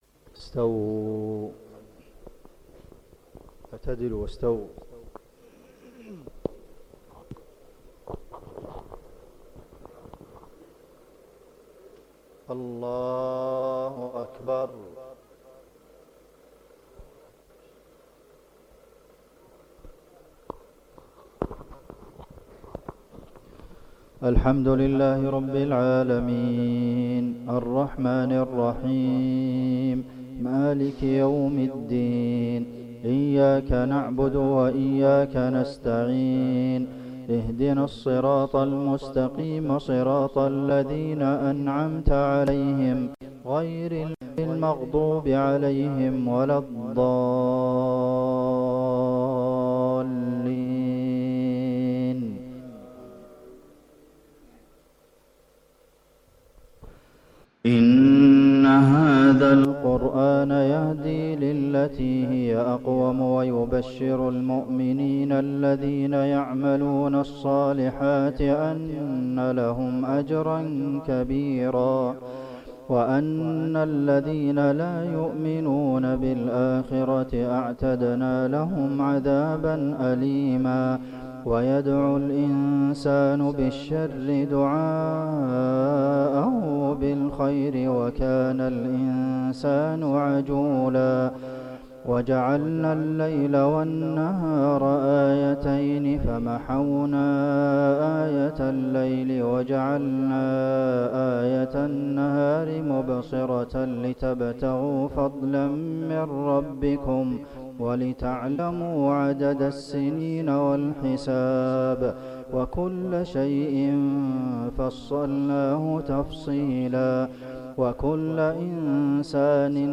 سورة الإسراء 9 - 15 | مغرب السبت 25 ربيع الأول 1446هـ | في جمهورية زامبيا > زيارة الشيخ عبدالمحسن القاسم لـ جمهورية زامبيا > تلاوات و جهود الشيخ عبدالمحسن القاسم > المزيد - تلاوات الحرمين